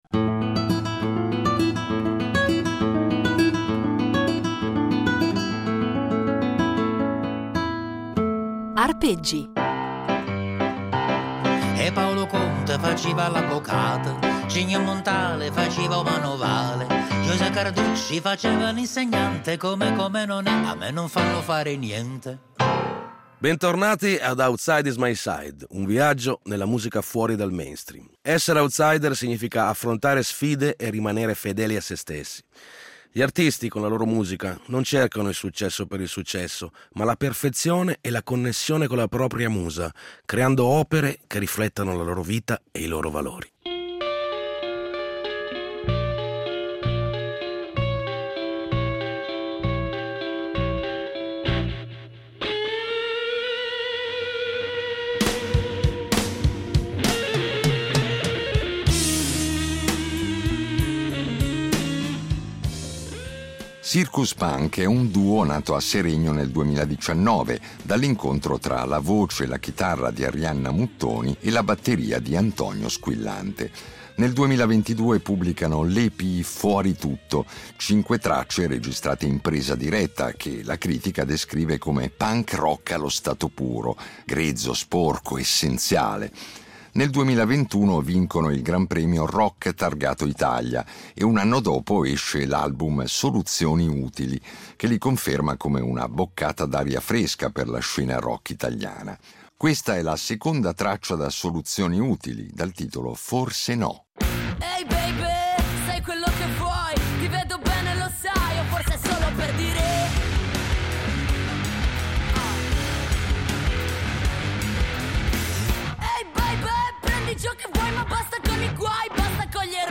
Sì, divertire, perché la musica d’autore è anche molto divertente e in queste 10 puntate ce ne accorgeremo, grazie alla disponibilità di dieci outsider o presunti tali, che ci offriranno dal vivo anche un assaggio della loro arte.